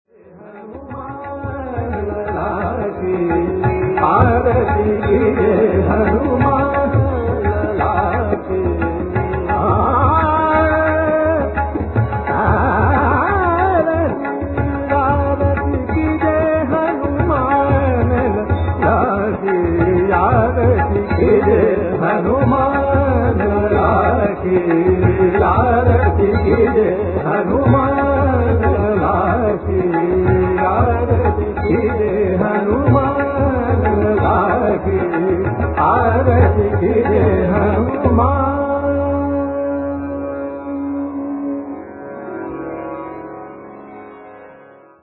Hanuman- Bhajan